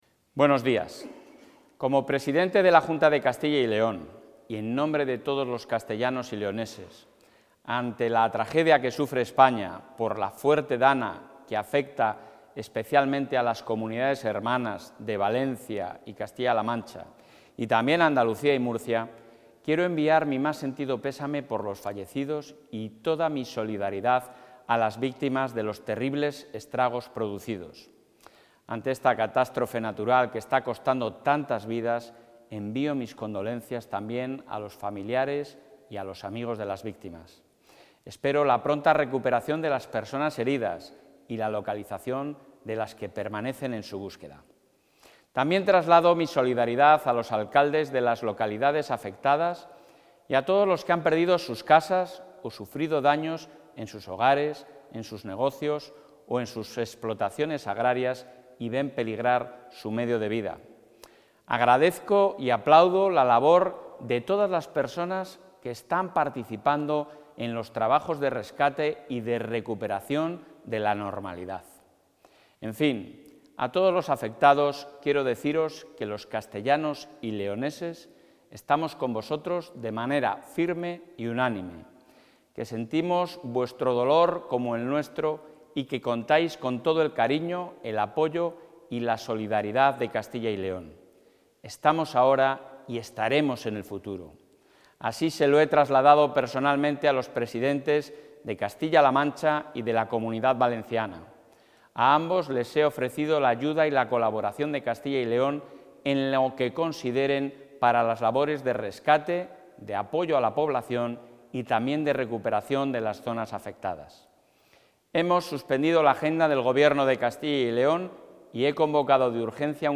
Declaración institucional.